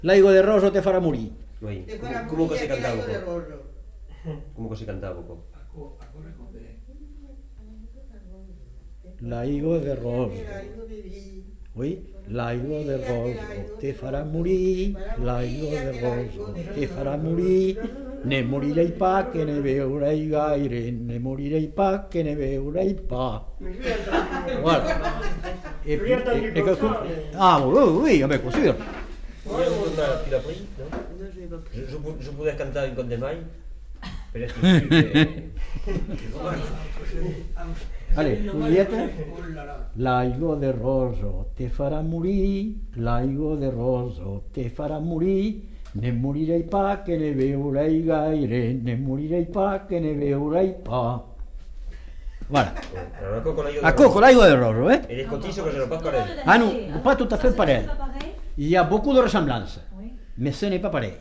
Aire culturelle : Agenais
Genre : chant
Effectif : 1
Type de voix : voix d'homme
Production du son : chanté
Danse : scottish